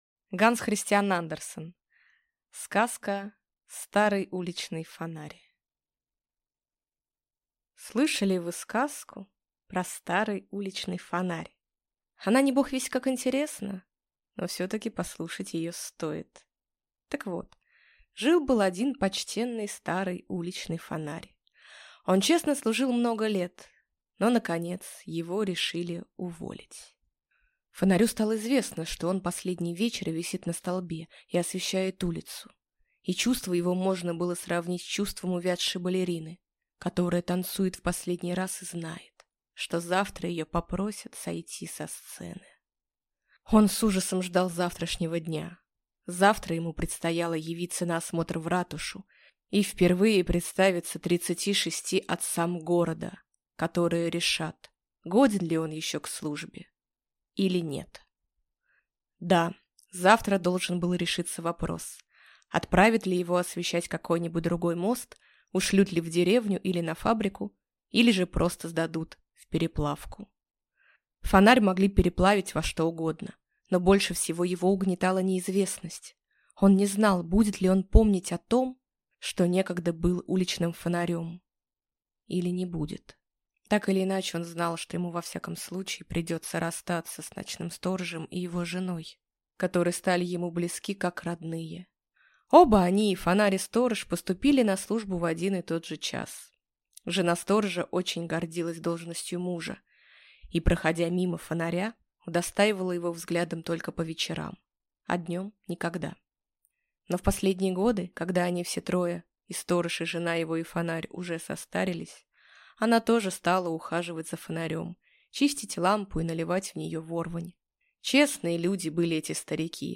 Аудиокнига Старый уличный фонарь | Библиотека аудиокниг